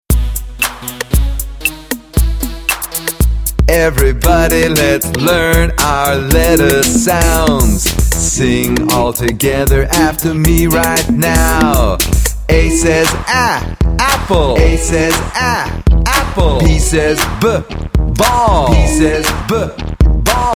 Alphabet Song Lyrics and Sound Clip